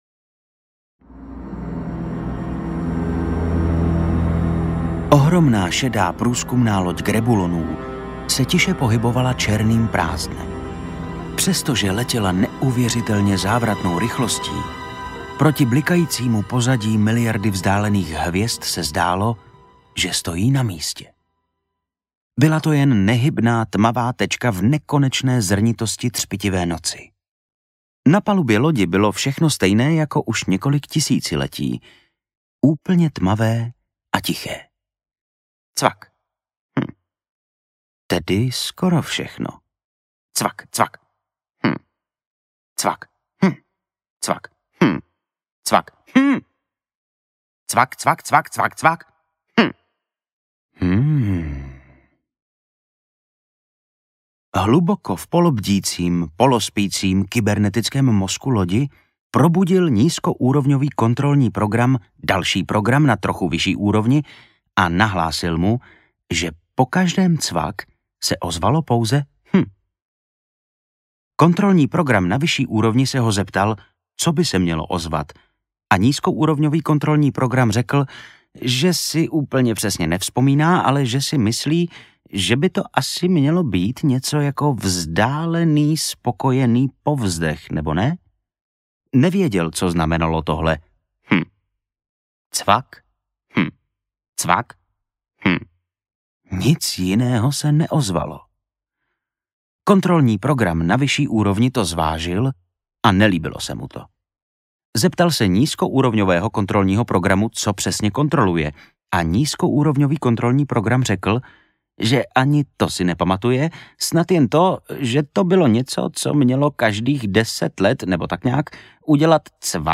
Ukázka z knihy
• InterpretVojtěch Kotek